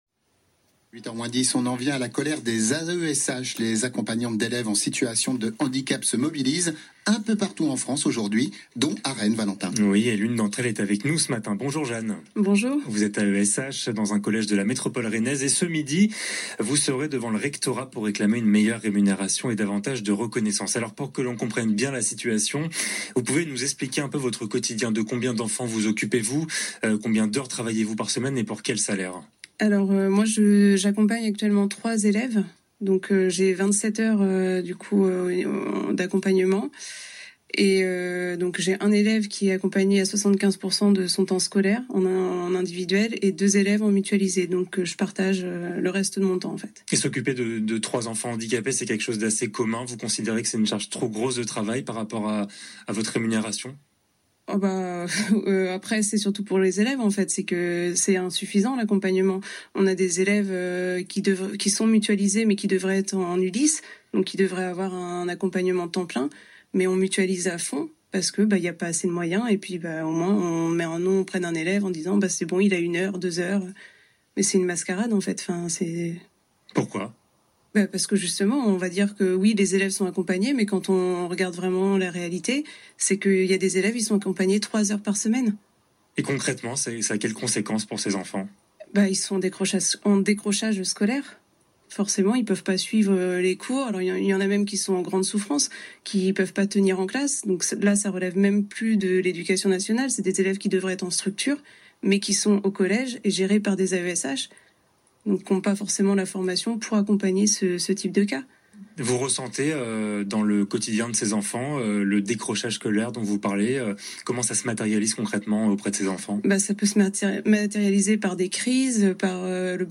Cette mobilisation importante a été couverte par de nombreux médias (radio, TV…), qui ont notamment interviewé plusieurs AESH adhérentes FO.